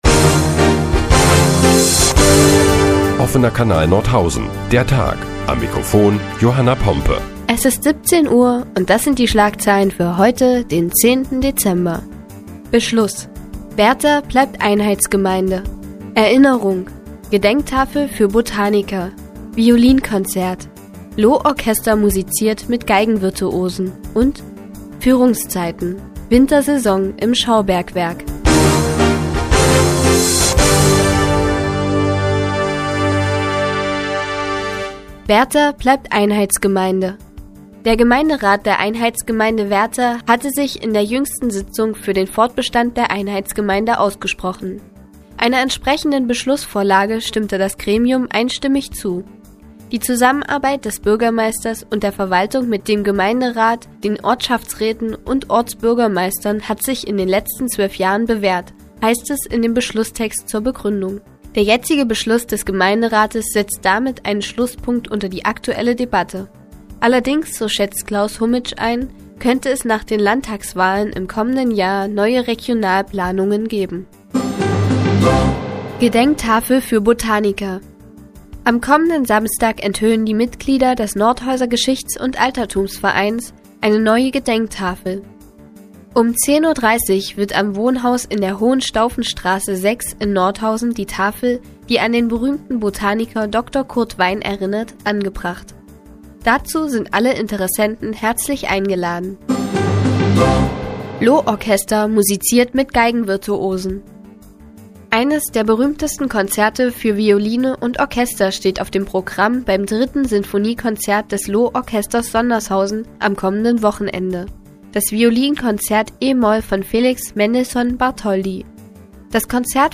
Die tägliche Nachrichtensendung des OKN ist nun auch in der nnz zu hören. Heute geht es unter anderem um ein Sinfoniekonzert des Loh-Orchesters Sondershausen und eine Gedenktafel für Botaniker Dr. Kurt Wein.